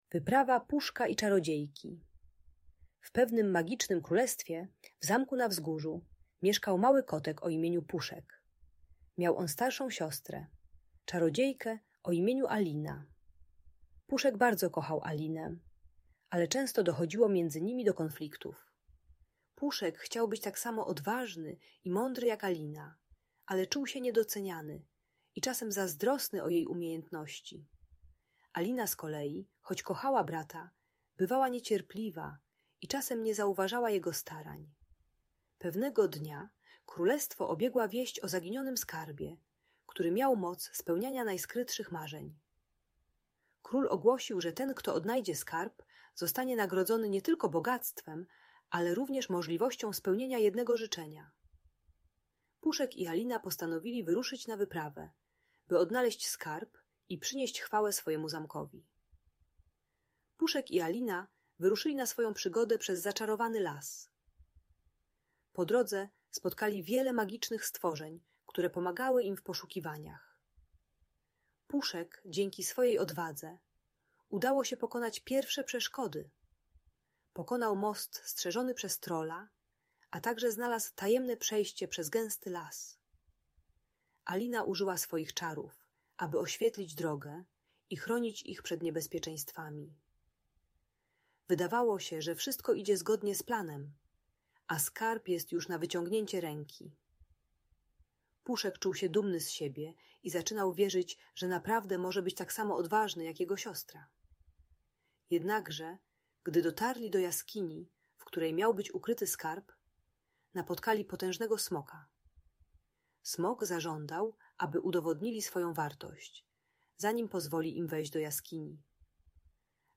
Przygody Puszka i czarodziejki Aliny - Audiobajka dla dzieci